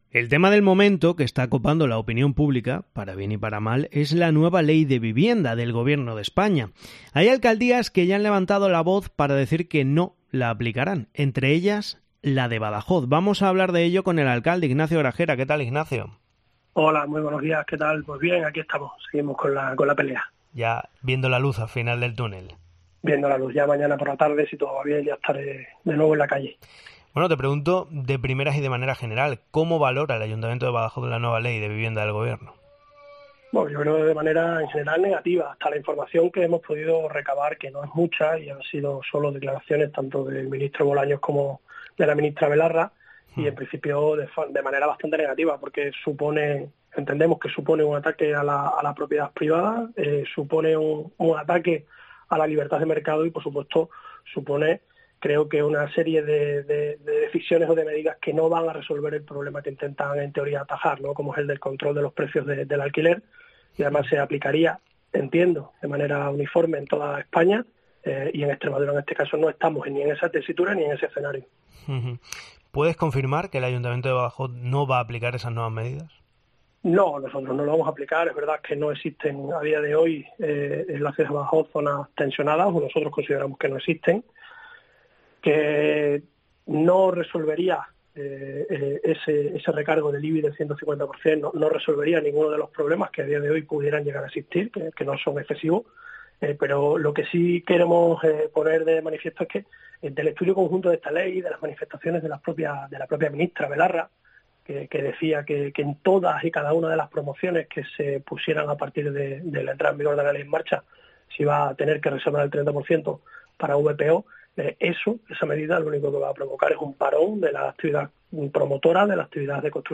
El alcalde de Badajoz critica en Cope la nueva iniciativa del Gobierno de España, a la que califica como un "ataque a la propiedad privada"